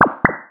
《プニョッなシステム音５》フリー効果音
プッニョッとかチュッピッという感じの効果音。システム音やプッニョッとした時に。